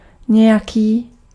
Ääntäminen
UK : IPA : /sʌm/ US : IPA : /sʌm/